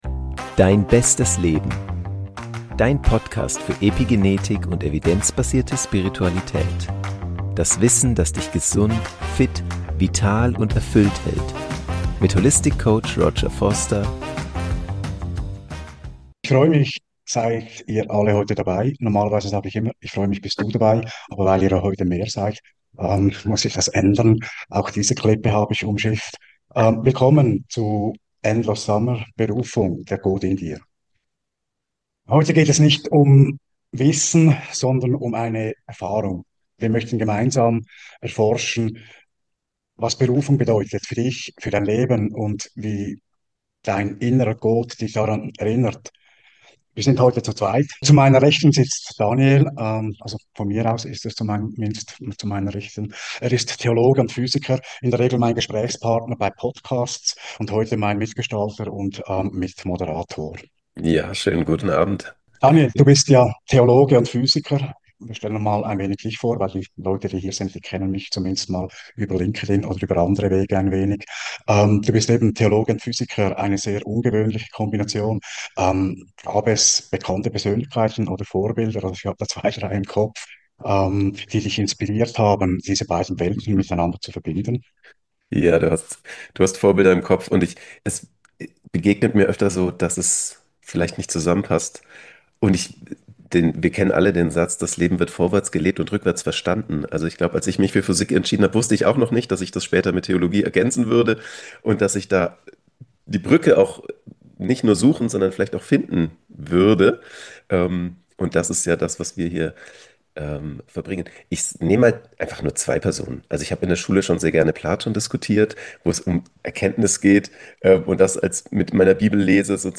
Du lernst spannendes Hintergrundwissen zu den 64 Genschlüsseln kennen und tauchst mit zwei Meditationen tiefer in Deine eigene Berufung ein.